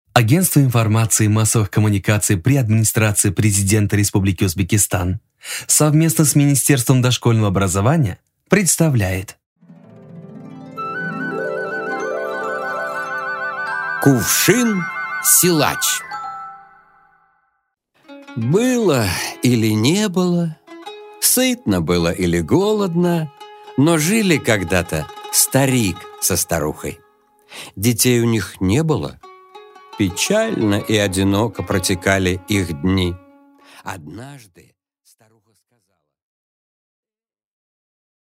Аудиокнига Кувшин-силач